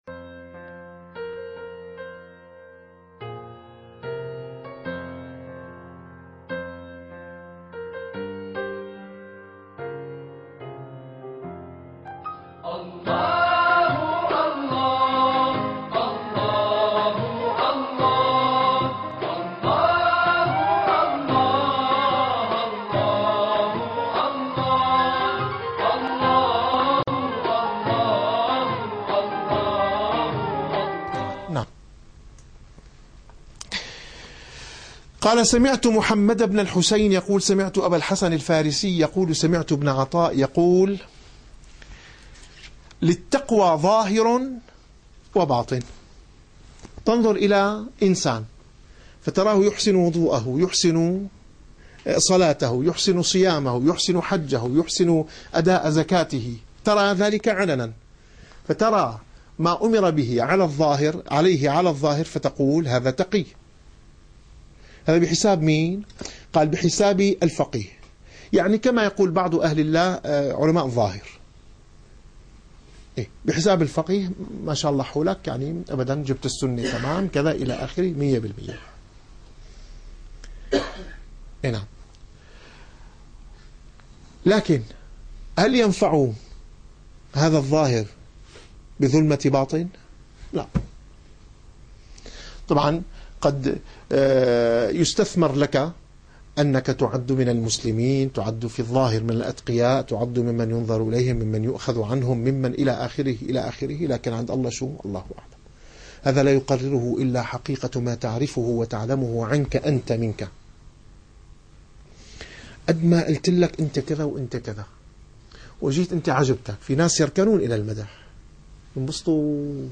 - الدروس العلمية - الرسالة القشيرية - الرسالة القشيرية / الدرس الواحد والخمسون.